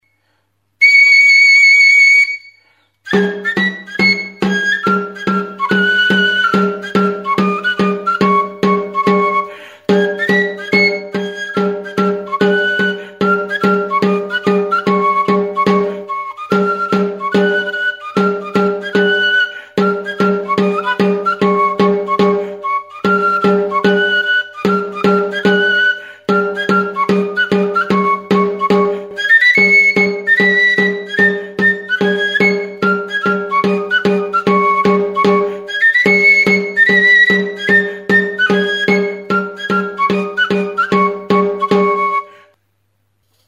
TXISTUA | Soinuenea Herri Musikaren Txokoa
Enregistr� avec cet instrument de musique.
Hiru zuloko flauta zuzena da. Zati batean eta Fa# tonu inguruan afinaturik.